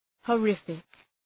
Προφορά
{hɔ:’rıfık}